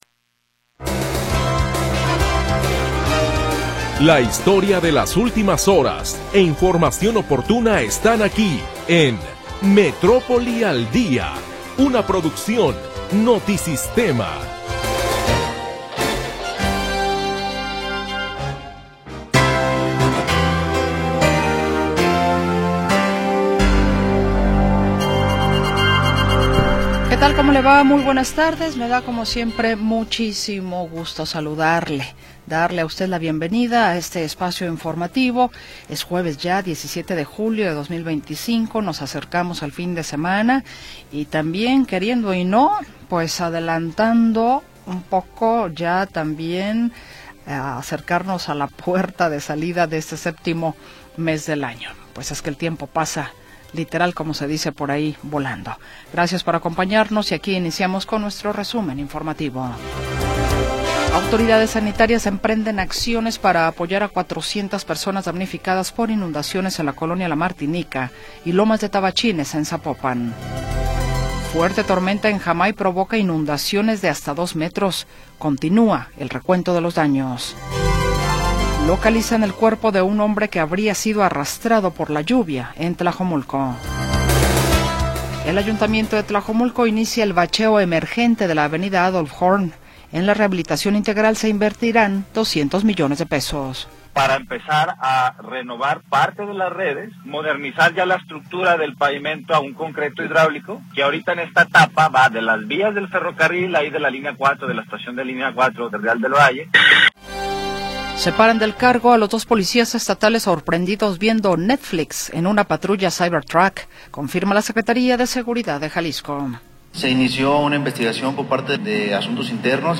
Primera hora del programa transmitido el 17 de Julio de 2025.